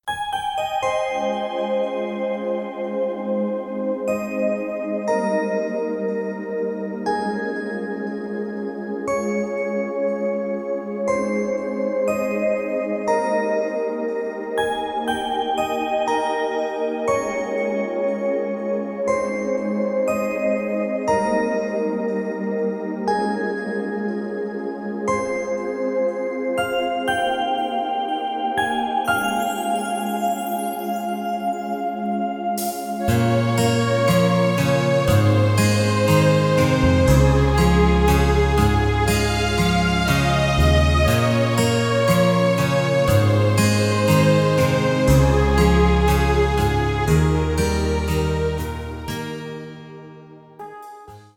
Gefühlvoll arrangierte Instrumentalmusik zum Planschen …